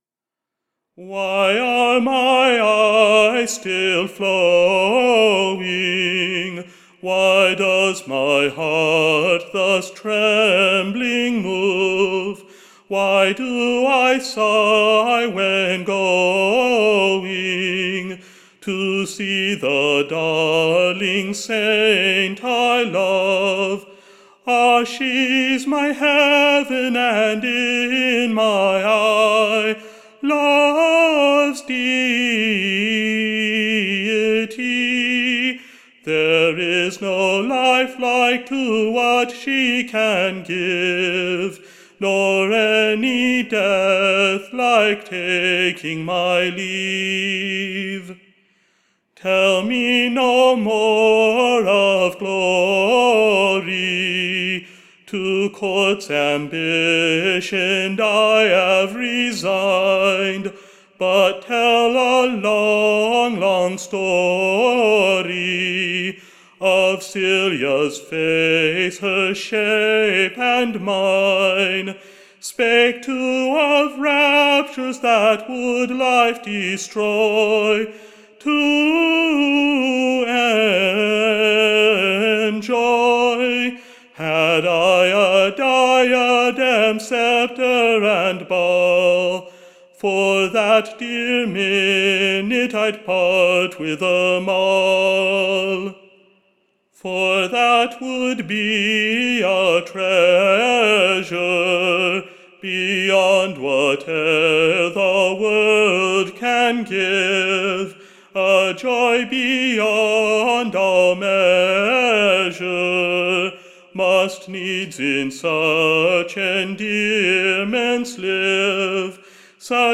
Recording Information Ballad Title The Constant LOVER: / OR, / Celia's Glory exprest to the Life. / A pleasant new Song (as it's sung after the Italian manner) and great in Request at / Court, and in the City.